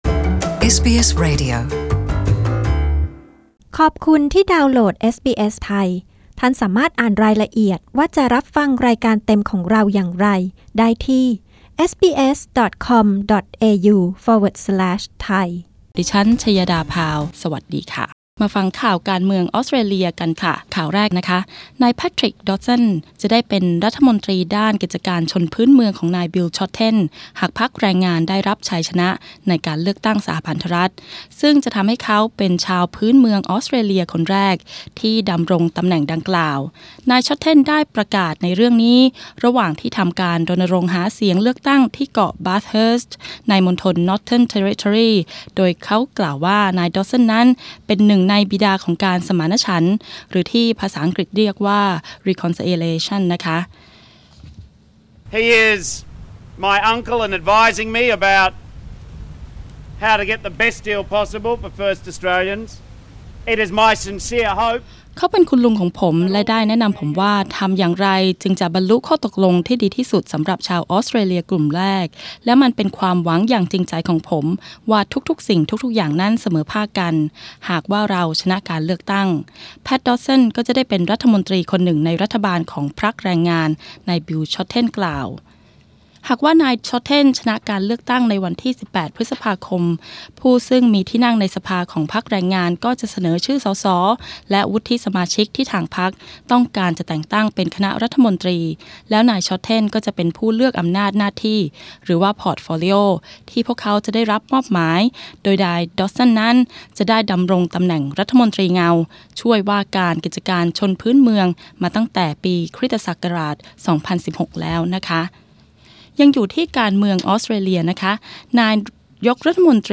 กดปุ่ม (▶) ด้านบนเพื่อฟังรายงานข่าวเกาะติดการเลือกตั้งสหพันธรัฐ